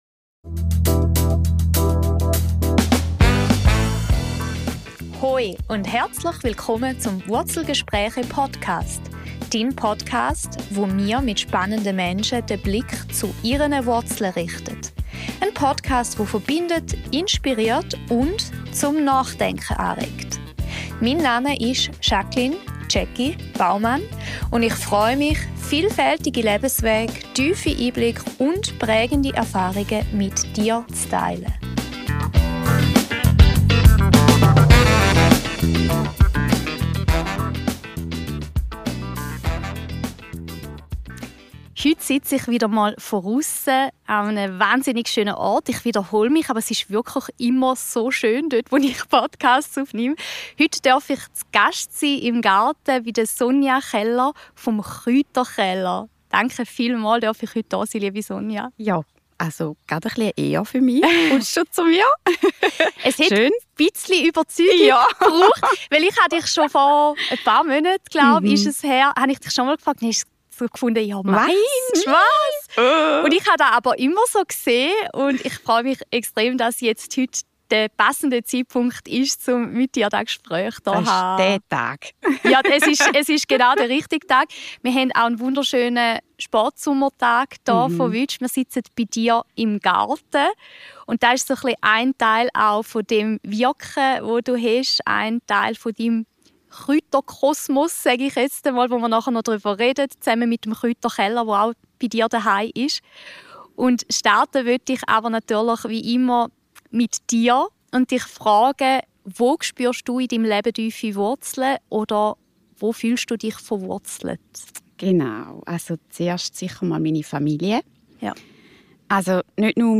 Ein Gespräch über Natürlichkeit, Bodenständigkeit, Kreativität und die Freude daran, mit der Natur im Einklang zu sein.